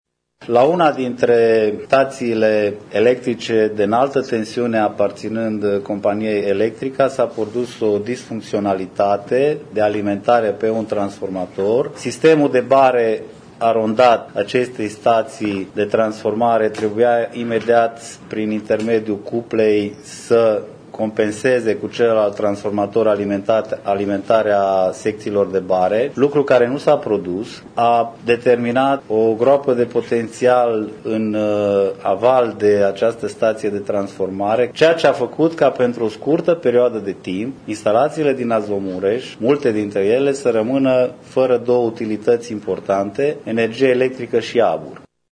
într-o conferință de presă